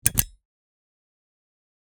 Download Button sound effect for free.